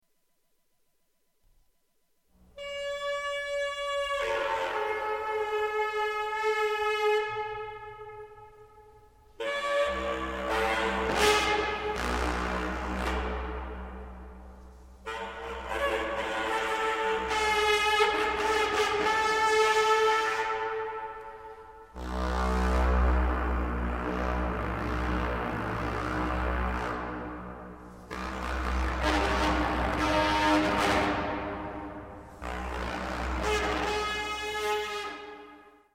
エレファントホース｜手づくり楽器 ～ 音 遊 具 ～